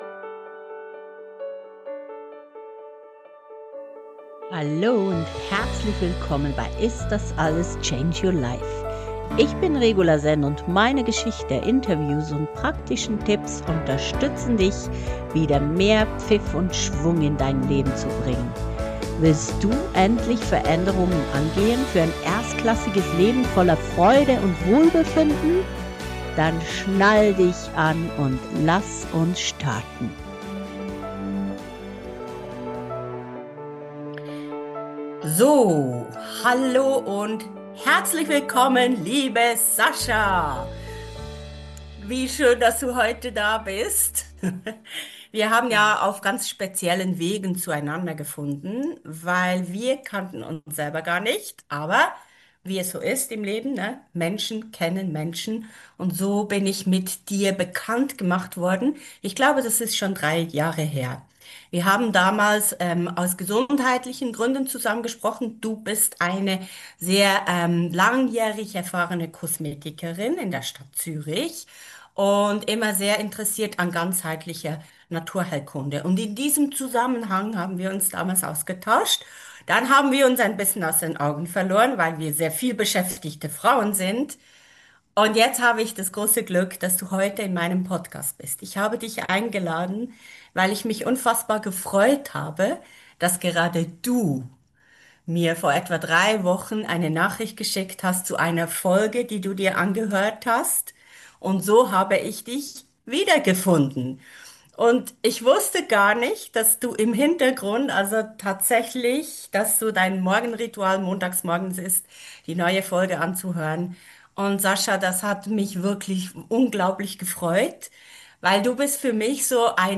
Folge 62 Interview